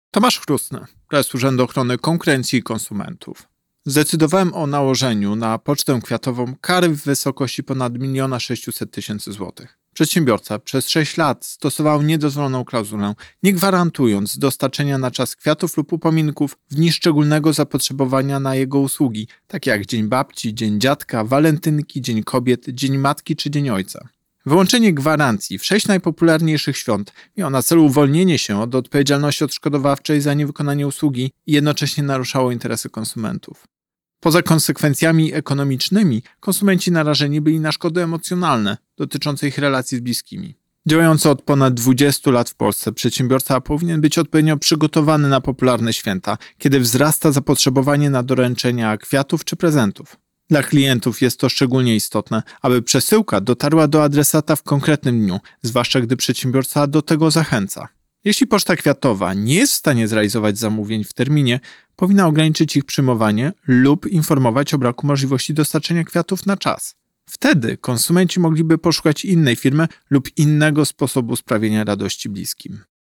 Pobierz wypowiedź Prezesa UOKiK Tomasza Chróstnego